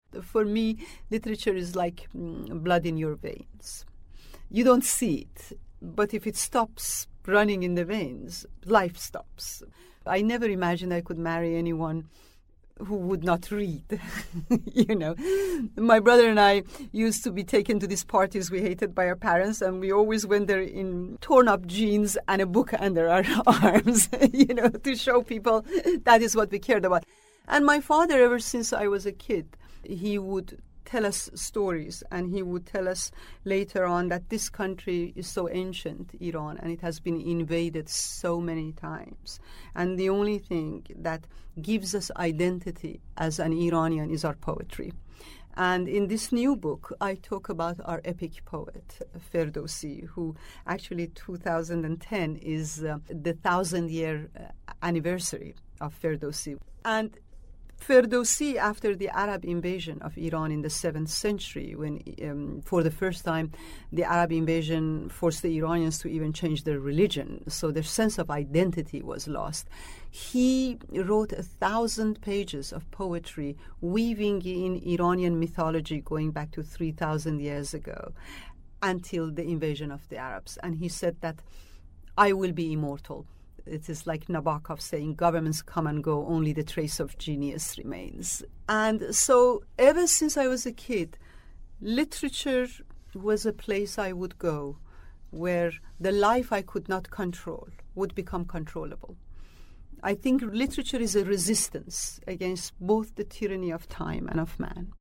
Transcript of conversation with Azar Nafisi